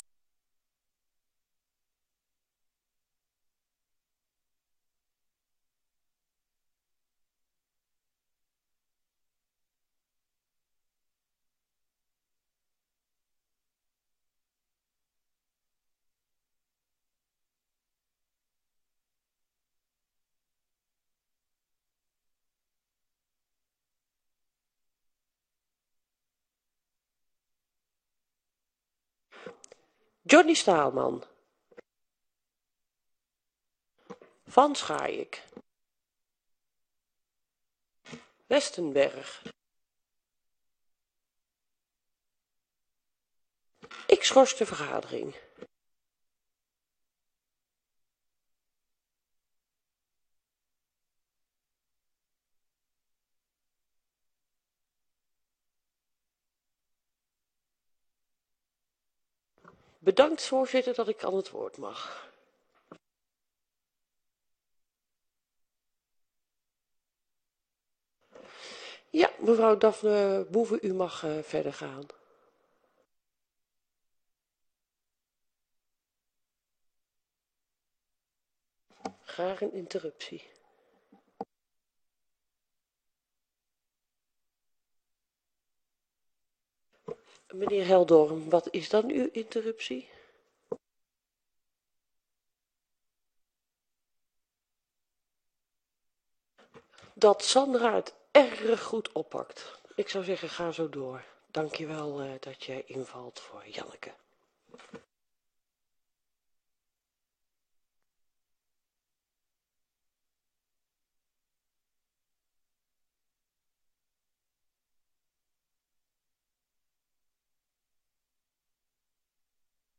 Audiotest door griffie 19 december 2024 15:30:00, Gemeente Woudenberg
Locatie: Raadzaal